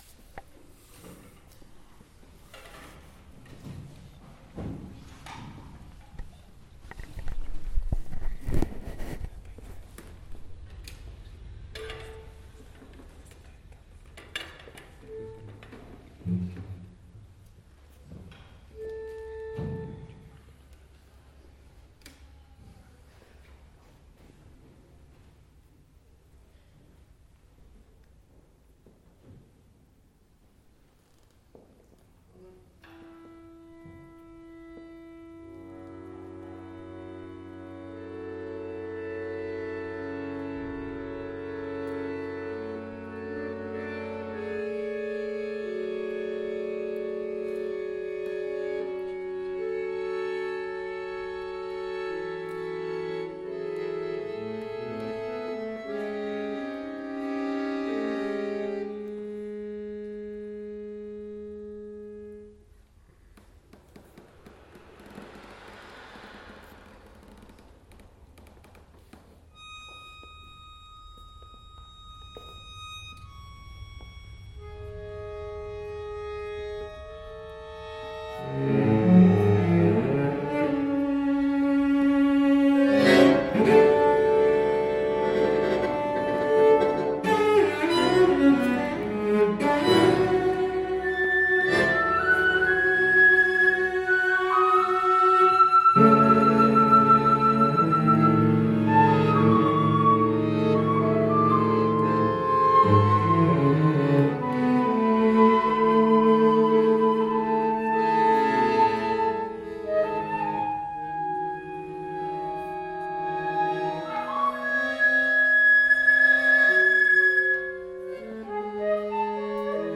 per soprano, flauto, fisarmonica e violoncello.